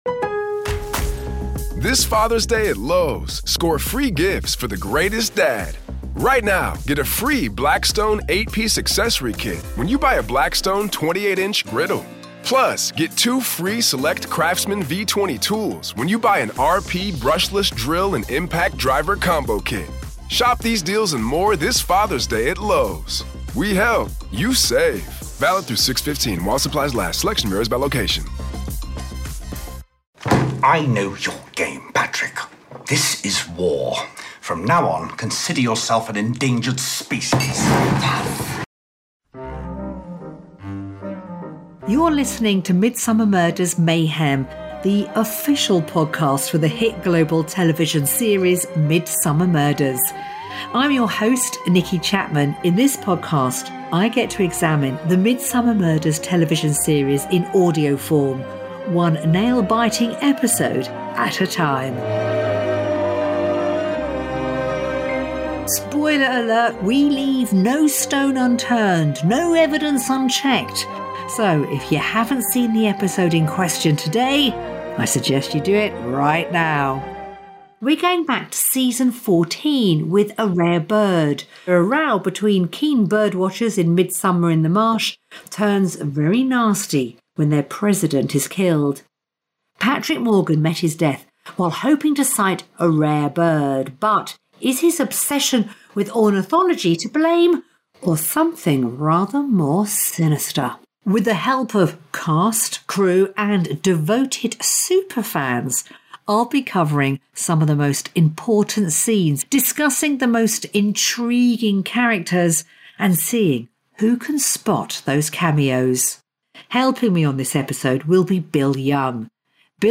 In this episode, we hear from actor Jason Hughes, otherwise known as DS Ben Jones plus we'll finish things off with a round of our superfan face off quiz, hosted by Ashley Storrie.